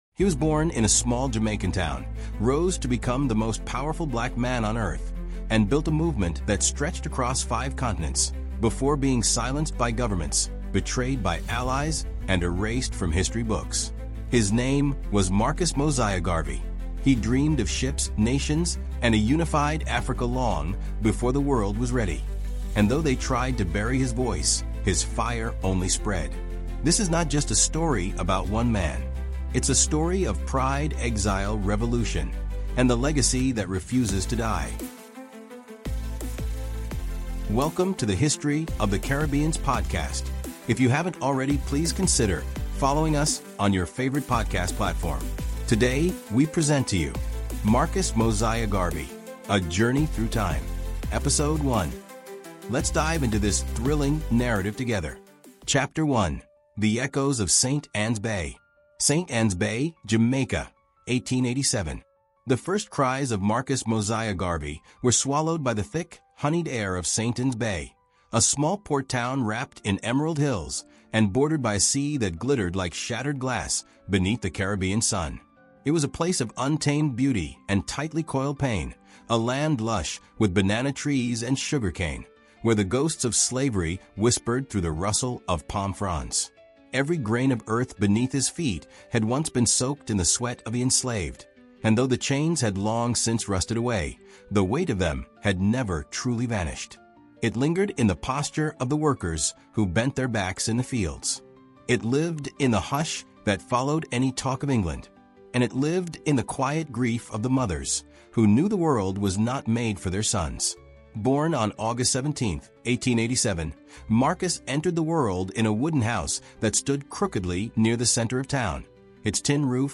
From his humble beginnings in St. Ann’s Bay, Jamaica, to becoming the architect of the world’s largest Black movement, this 16-chapter historical epic takes you deep into Garvey’s bold dreams, his rise to international prominence, his persecution by world powers, and the resurrection of his legacy through revolution, rhythm, and remembrance. Through rich storytelling and immersive narration, discover how Garvey inspired generations—from African presidents and civil rights leaders to Rastafarians, poets, and musicians across the globe.